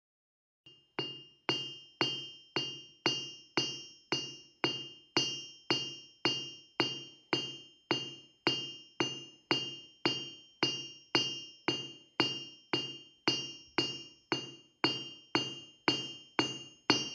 Anvil
Amboss-Hammer.mp3